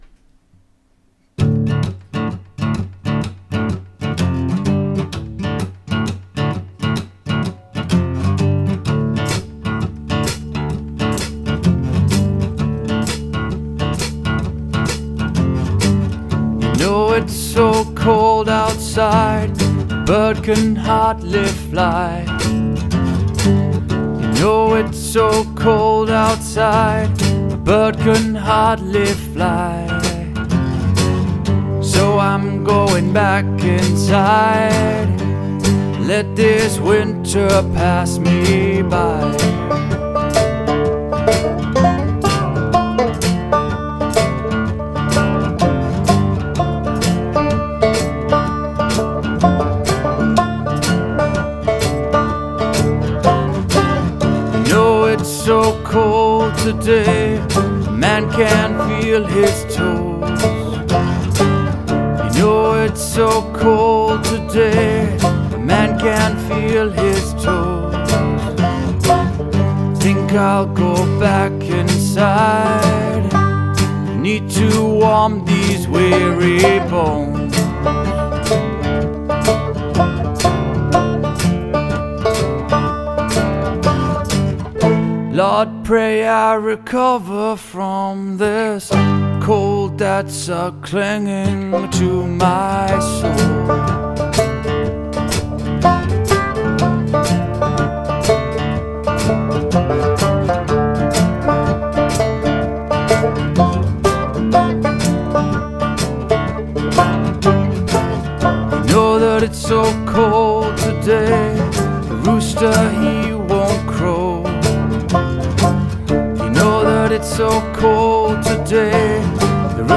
• Genre: Blues / Folk